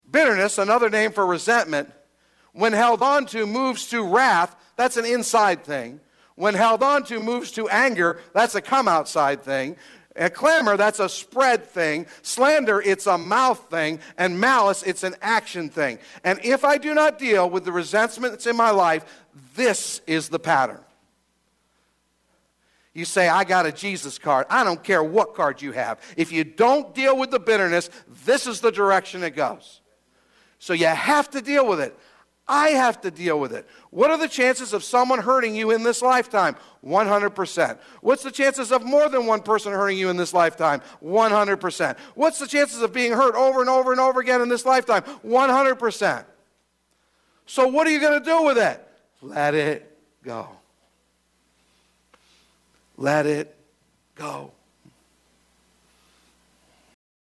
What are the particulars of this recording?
One last snippet from this past week’s message at Calvary Chapel – “Letting Go”